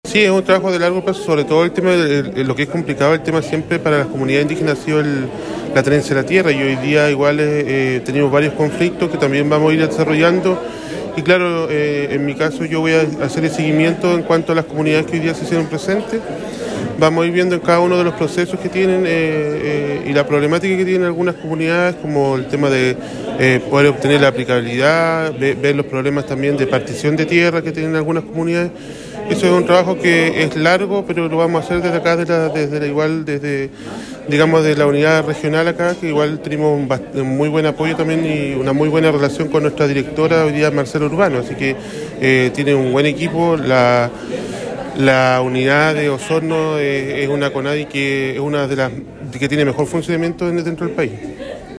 En relación a las problemáticas de la tenencia de tierra y reivindicaciones, se continuará trabajando en conjunto a la Dirección Regional de Conadi, como lo explicó Mario Inai.